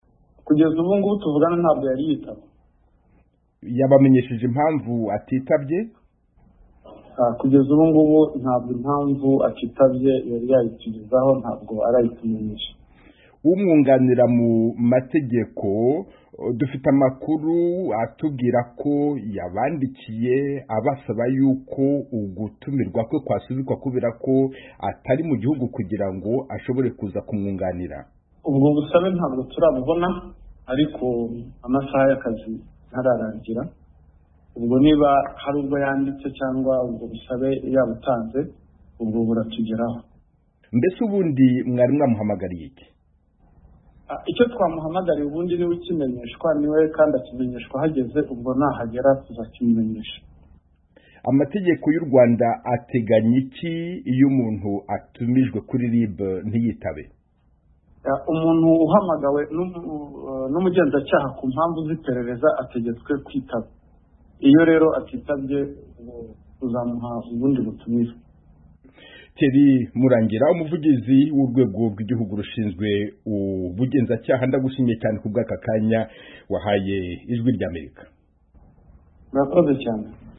Ikiganiro